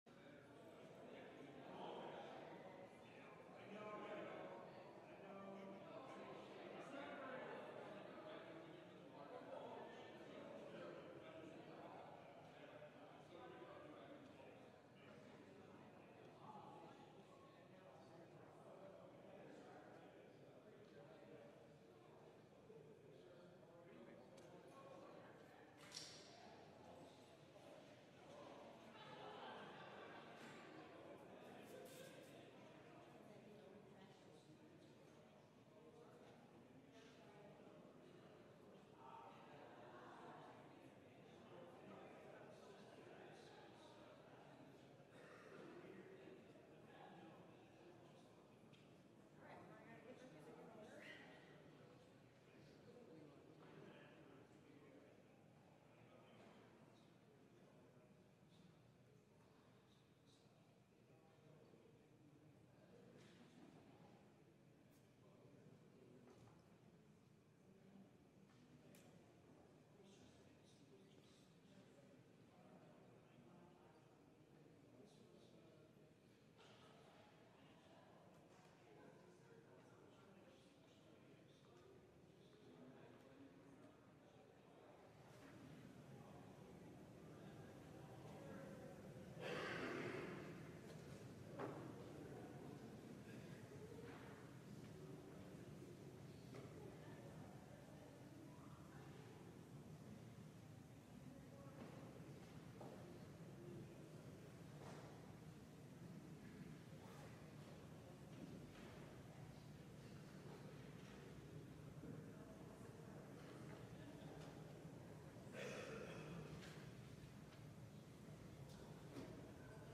LIVE Evening Worship Service - Walking On The Deep Water